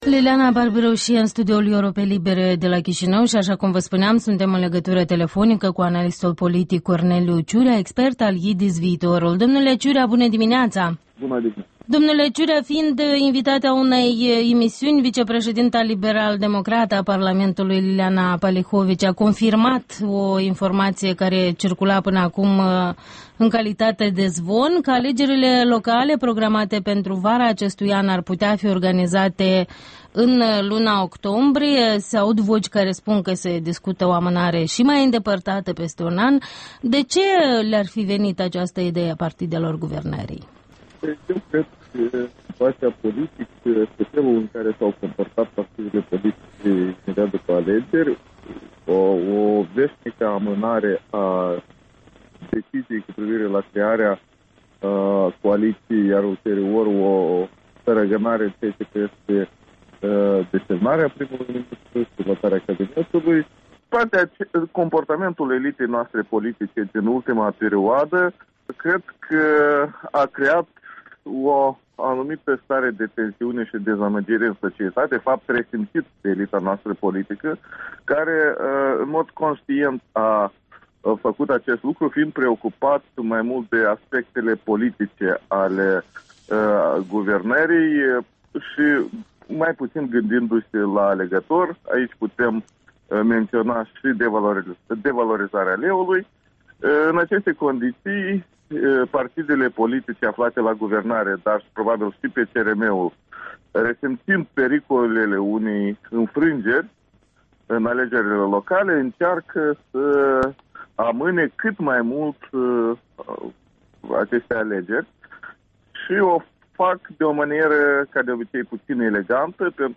Interviul dimineții cu expertul IDIS „Viitorul”.